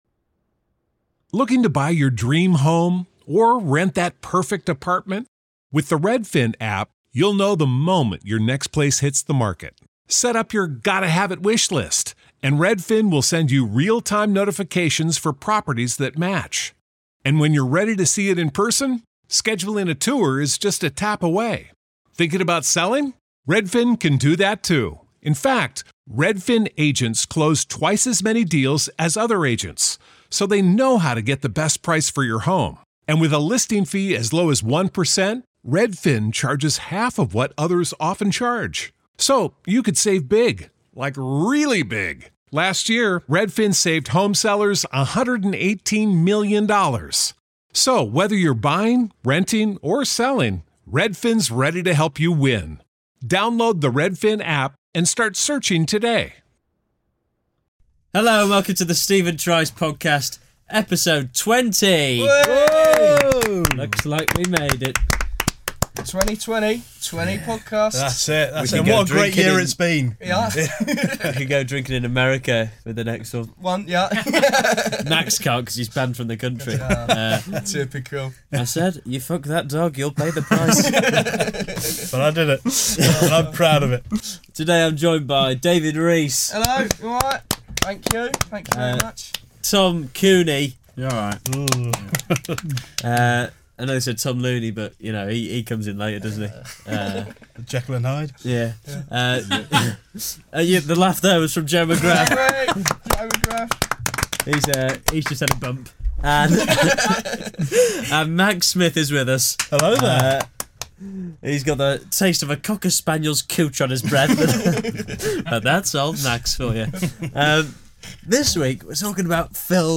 Comedy Interviews, Comedy